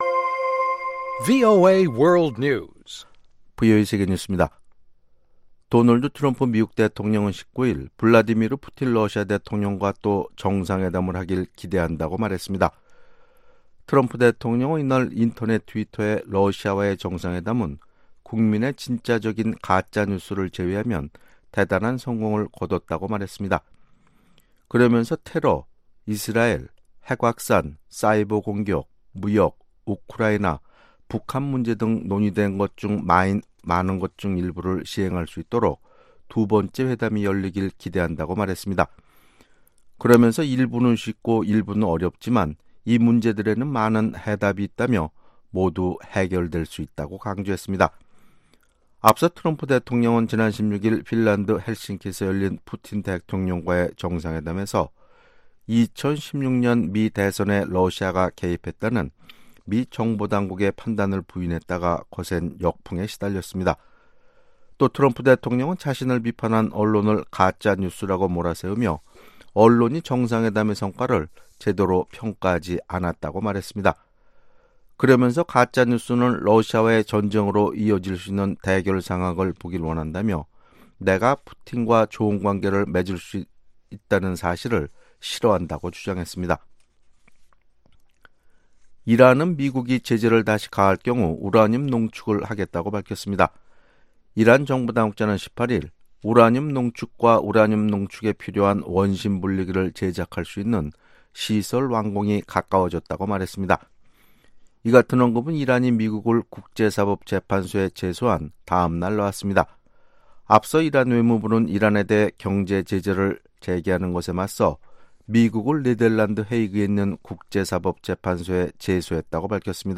VOA 한국어 아침 뉴스 프로그램 '워싱턴 뉴스 광장' 2018년 7월 20일 방송입니다. 미국의 상원의원들이 미-북 정상회담이 열린 지 한 달이 넘도록 회담 결과에 대한 정확한 보고를 받지 못했다고 우려하는 가운데 다음주 트럼프 행정부의 대북 전략을 집중 추궁하는 청문회가 열립니다. 한국 정부의 대북제재 결의 2397호에 따른 이행보고서가 공개됐습니다.